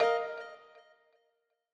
Longhorn XP - Default.wav